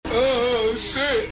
Nervous Oh Shit - Amusement Ride Prank (Sound Effect)
NervousOhShit-AmusementRidePrank(SoundEf+(1).mp3